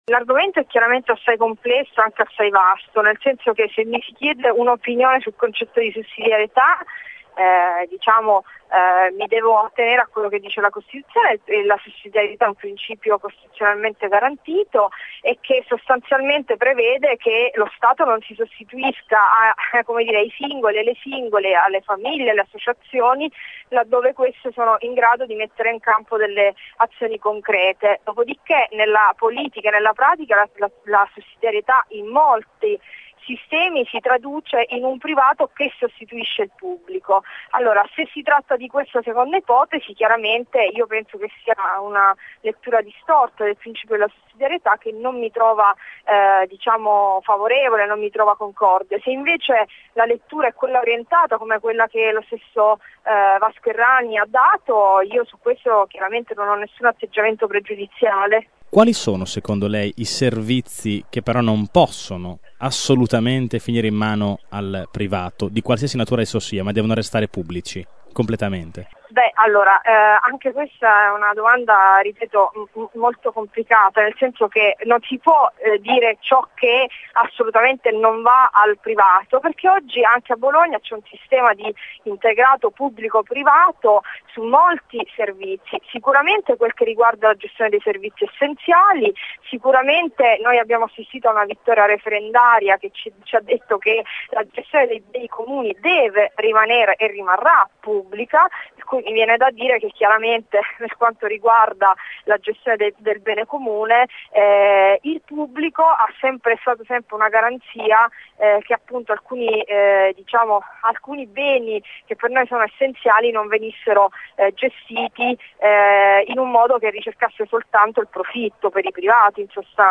5 ott. – In tema di sussidiarietà la consigliera comunale di Sel Cathy La Torre è d’accordo con il presidente della Regione Vasco Errani. Lo ha detto oggi ai nostri microfoni precisando che se per sussidiarietà di intende la sostituzione dei privati al pubblico La Torre è contraria.